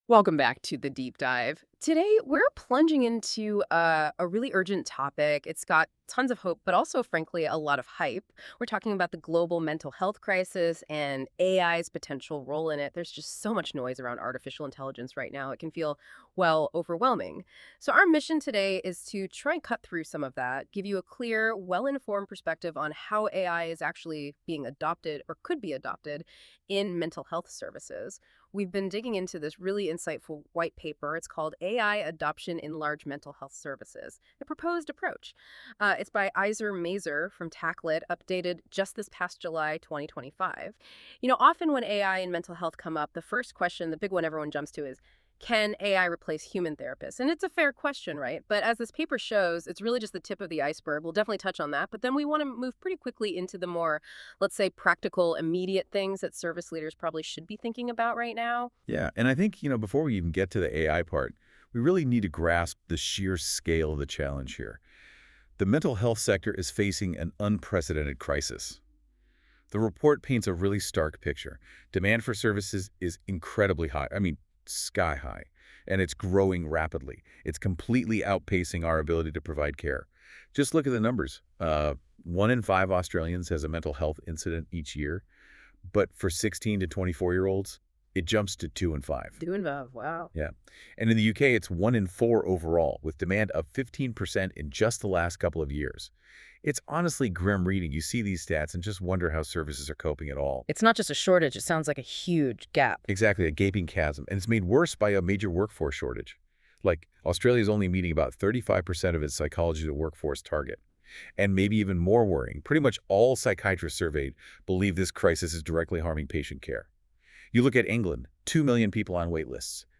A podcast discussion.
We have recently released a white paper on how large mental health services can adopt AI safely and effectively. To accompany it, we created an 18 minute podcast produced entirely by AI. The clarity and natural delivery of the AI podcasters are a useful reminder of what is now possible (including the small glitches!).